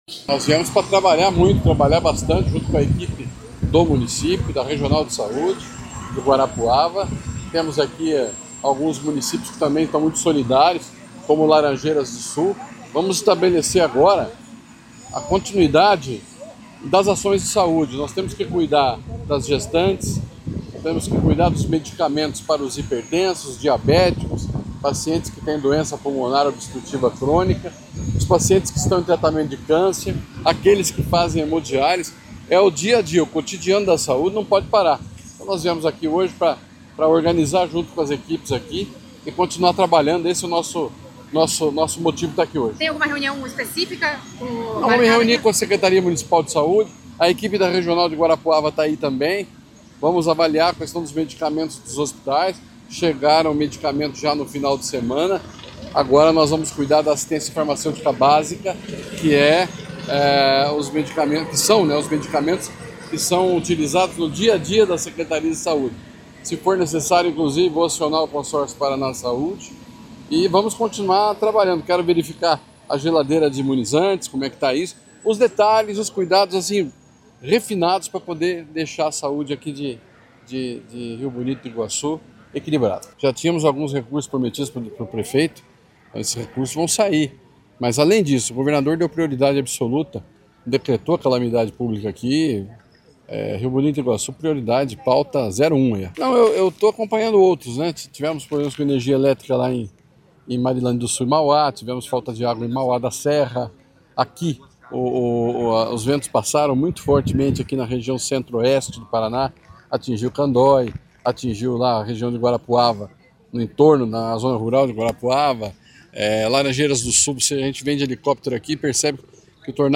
Sonora do secretário da Saúde, Beto Preto, sobre as iniciativas de reconstrução para a comunidade de Rio Bonito do Iguaçu | Governo do Estado do Paraná